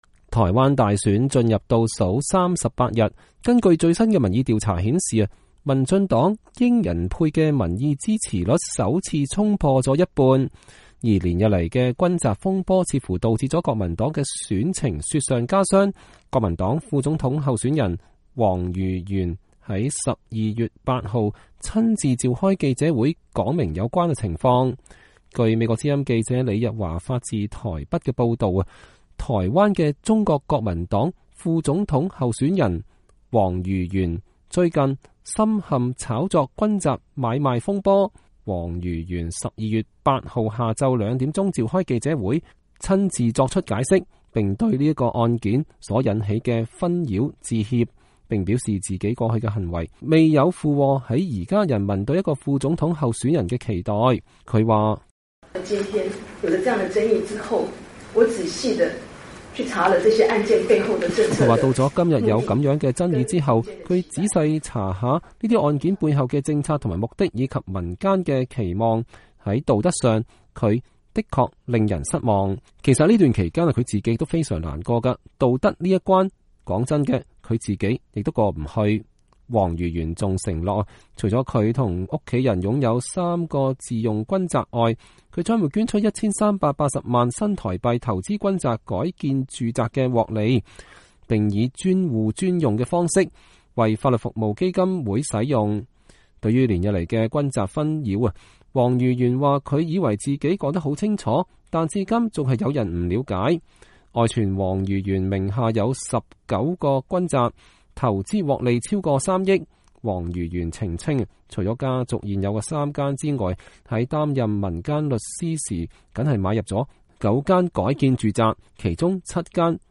王如玄召開軍宅問題記者會